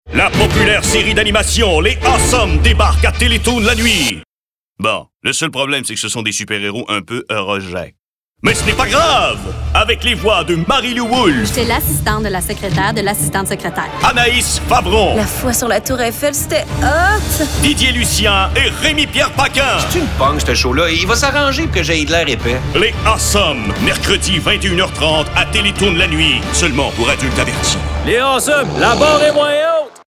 Diep, Vertrouwd, Volwassen